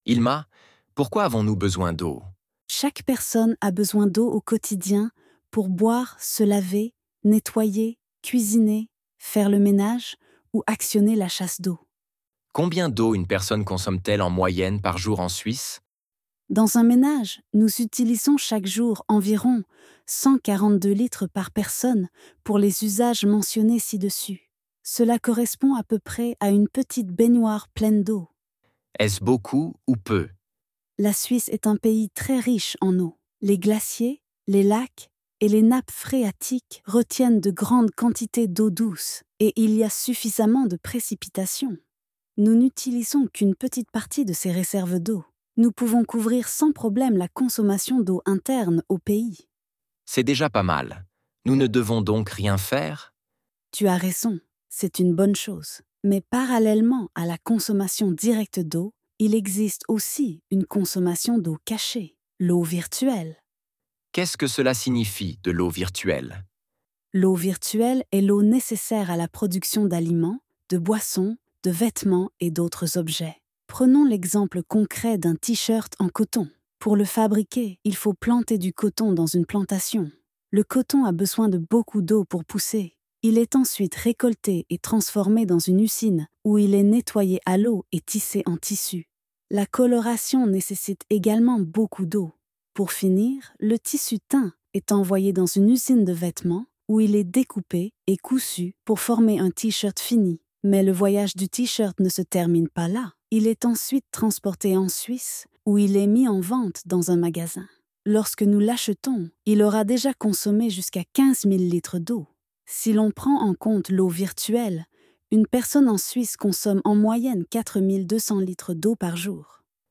Foodchampions C2 Mo5 Fichier Audio De L'interview
foodchampions_c2_mo5_fichier-audio-de-linterview.wav